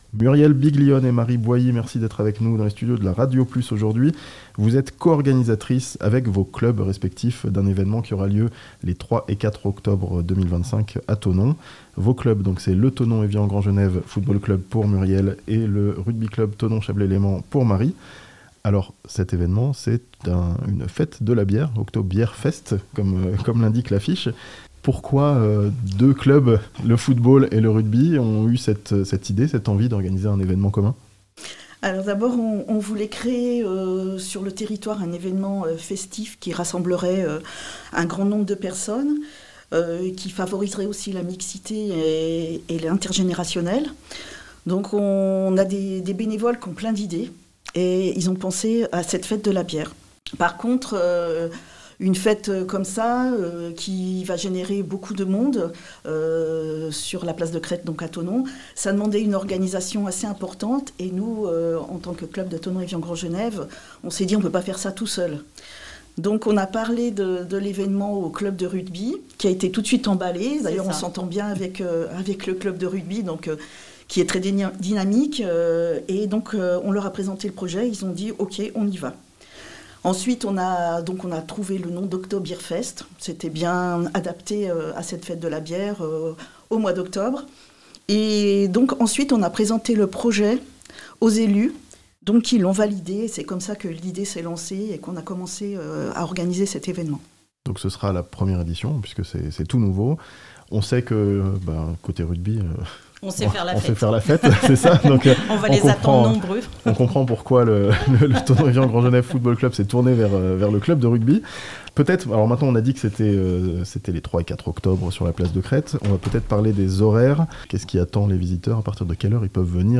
Football et rugby s'unissent à Thonon pour une grande fête de la bière (interview)